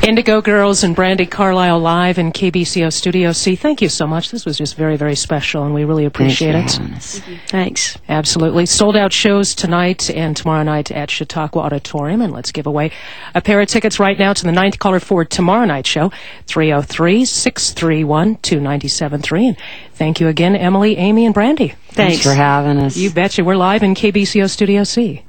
lifeblood: bootlegs: 2007-06-20: kbco - boulder, colorado (with brandi carlile)
07. interview (0:25)